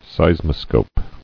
[seis·mo·scope]